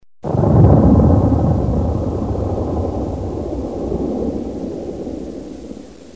جلوه های صوتی
دانلود صدای بمب و موشک 24 از ساعد نیوز با لینک مستقیم و کیفیت بالا